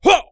人声采集素材/男3战士型/ZS发力4.wav